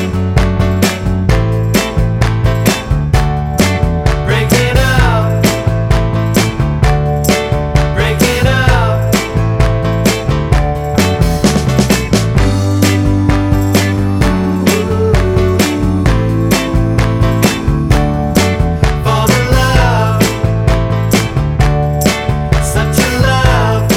Without Acoustic Guitar Pop (1970s) 3:13 Buy £1.50